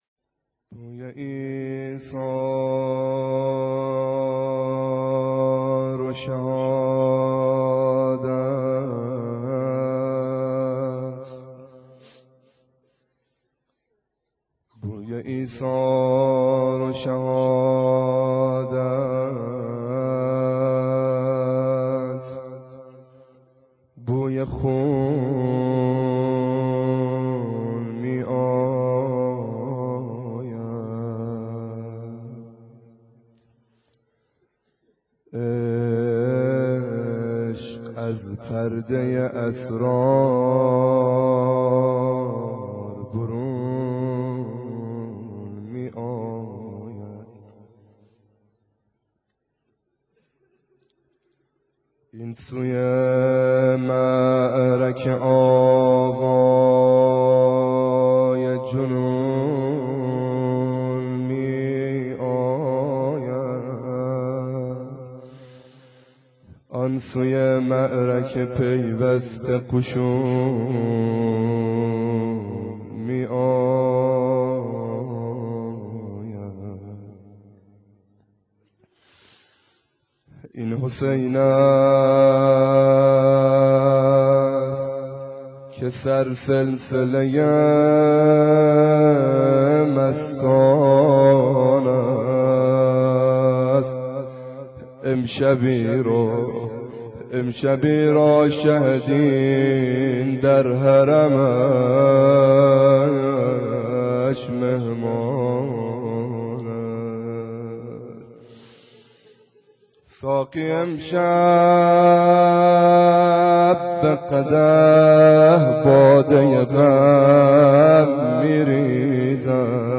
شب عاشورا _ روضه